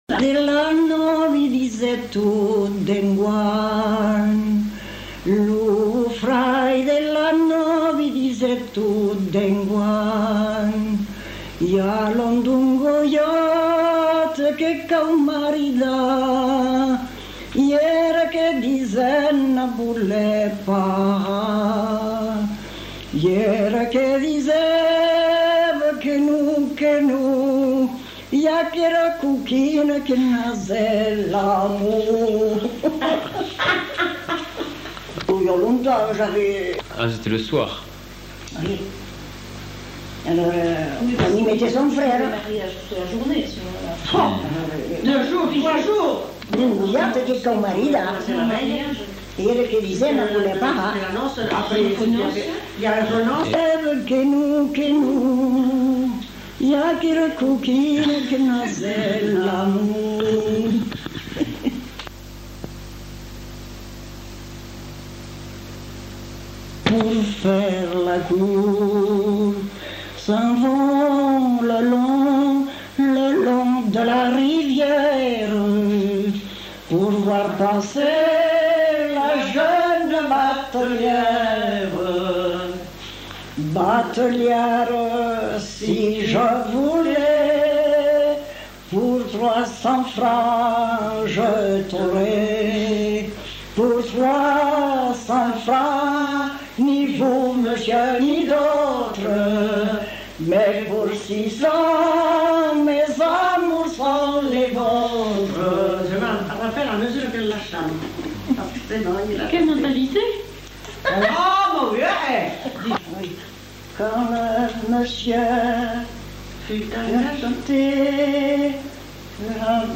Lieu : Mont-de-Marsan
Genre : chant
Type de voix : voix de femme
Production du son : chanté
Notes consultables : Les premiers mots ne sont pas enregistrés. En fin de séquence, bribes de deux chants.